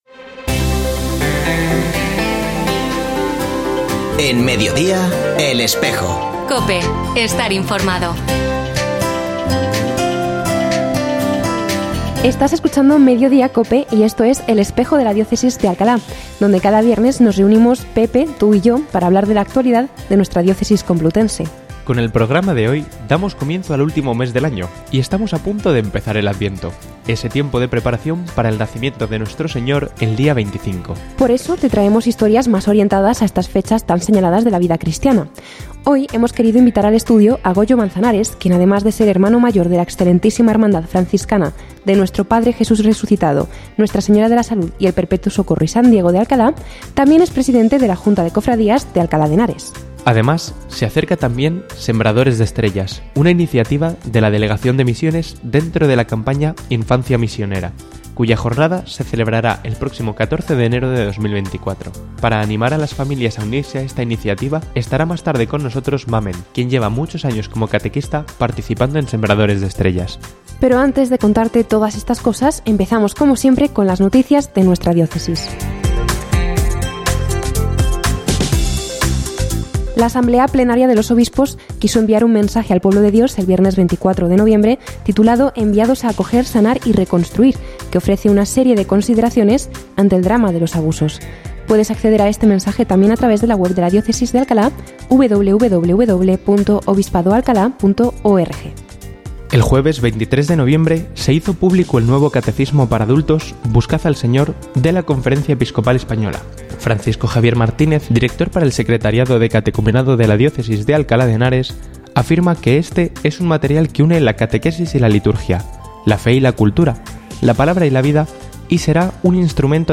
Escucha otras entrevistas de El Espejo de la Diócesis de Alcalá
Ofrecemos el audio del programa de El Espejo de la Diócesis de Alcalá emitido hoy, 1 de diciembre de 2023, en radio COPE.